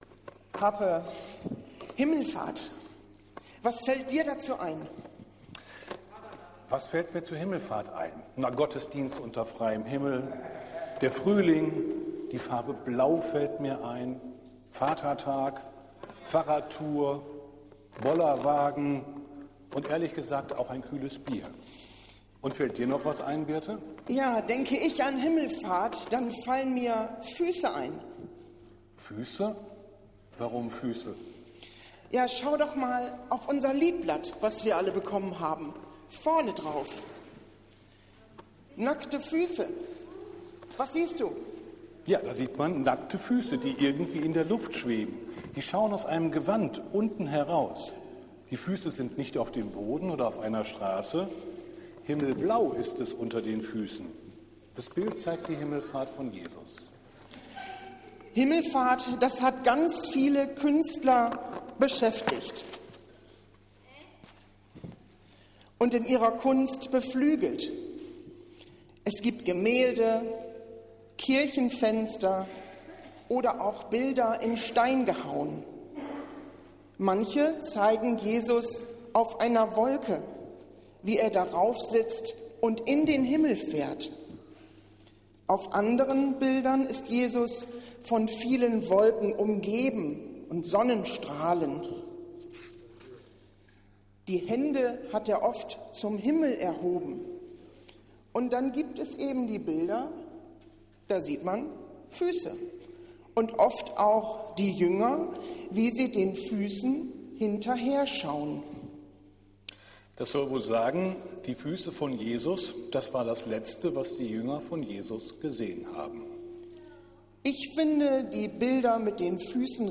Es handelte sich um einen Gottesdienst in einfacher Sprache.